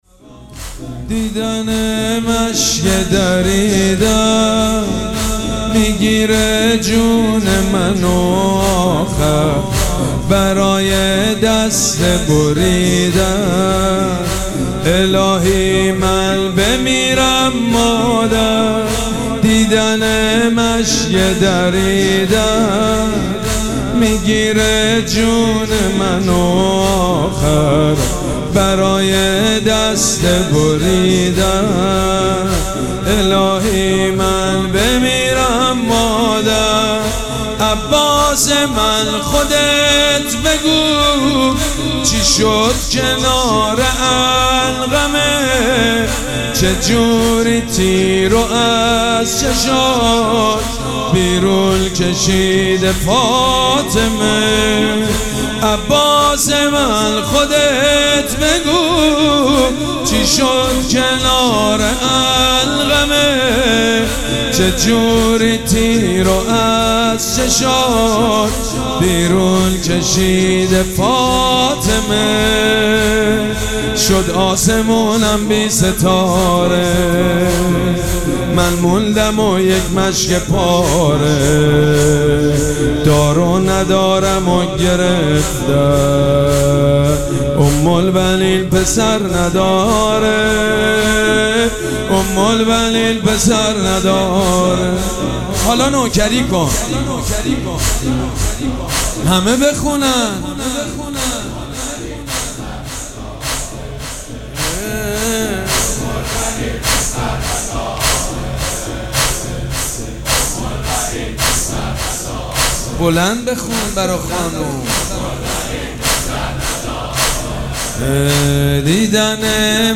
شب چهارم مراسم عزاداری اربعین حسینی ۱۴۴۷
مداح
حاج سید مجید بنی فاطمه